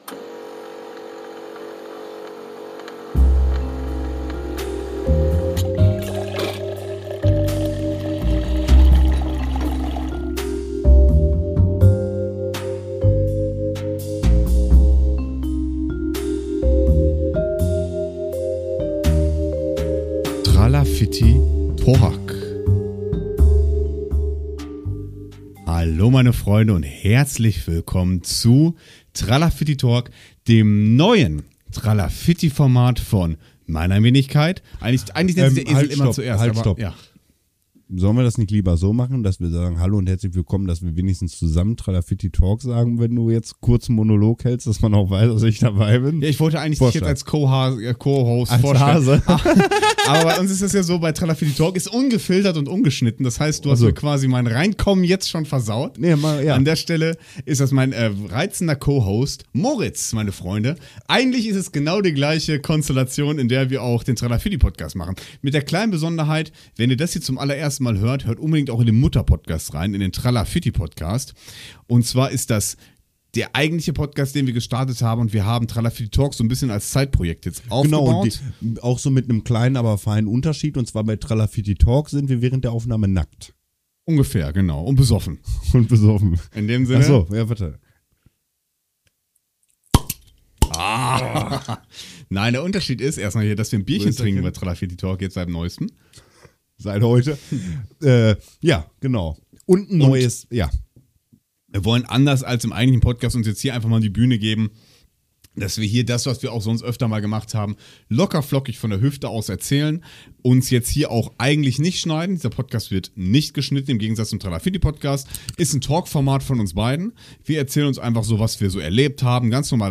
Er fungiert als ein klassischer Laberpodcast, ähnlich wie Lanz und Precht, in dem die wichtigen Fragen und Ereignisse des kleinen Mannes noch einmal besprochen werden. Ohne roten Faden und ohne Vorbereitung, einfach locker und ungeskriptet.